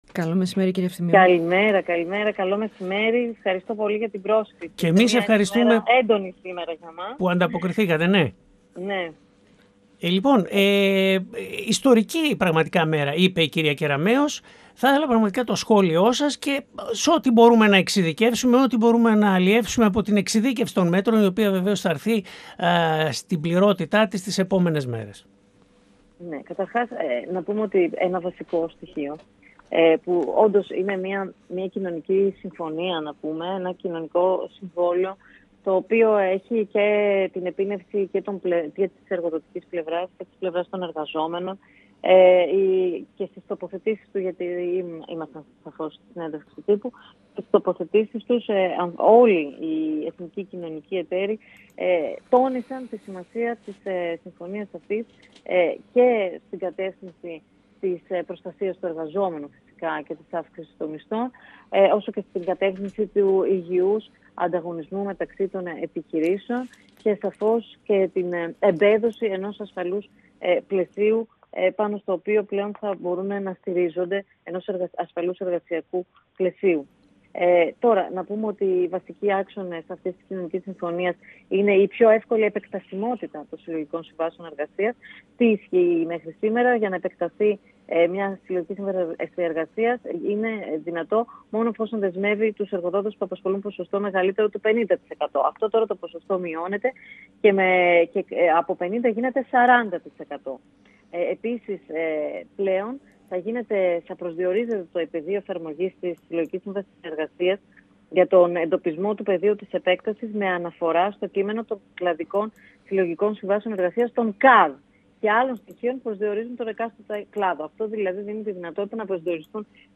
Τους βασικούς άξονες των σημερινών ανακοινώσεων του Υπουργείου Εργασίας και των κοινωνικών εταίρων για την επαναφορά των Συλλογικών Συμβάσεων Εργασίας εξειδίκευσε η Υφυπουργός Εργασίας, Άννα Ευθυμίου, μιλώντας στη ραδιοφωνική εκπομπή του 102fm «Επόμενη Στάση: Ενημέρωση»